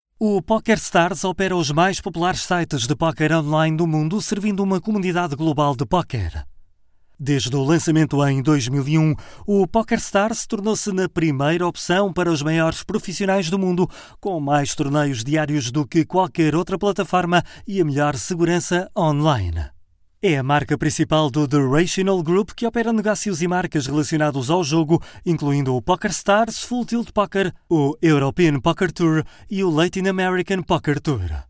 Portuguese, Male, Home Studio, 30s-40s